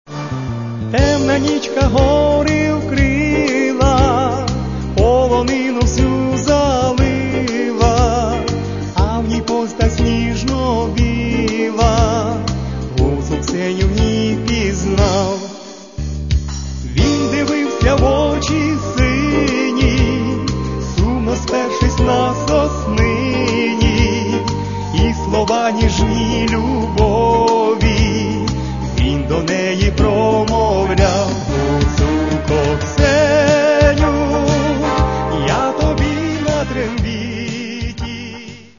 Каталог -> MP3-CD -> Эстрада